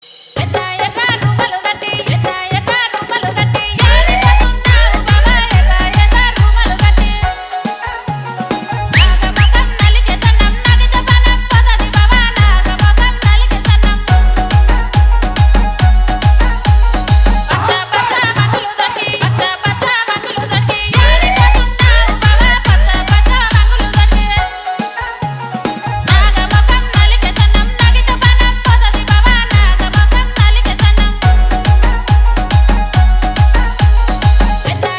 love song ringtone
dance ringtone download